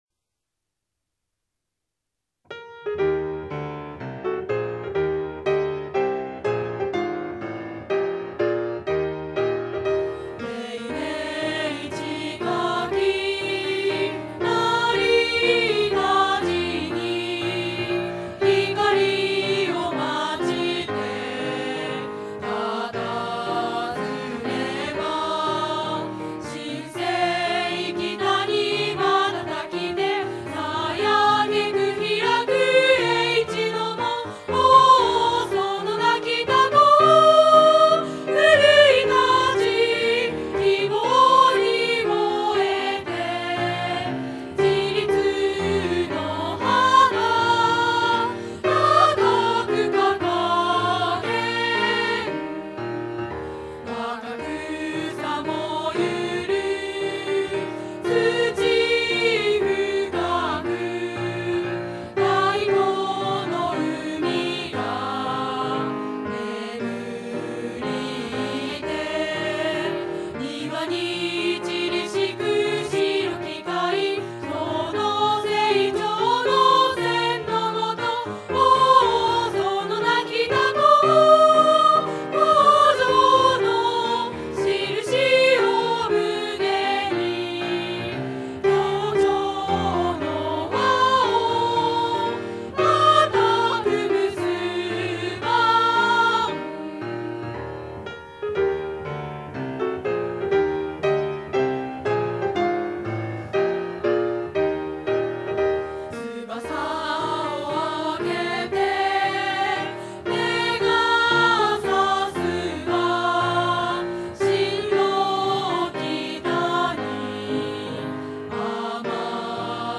校歌(WMA_2.38MB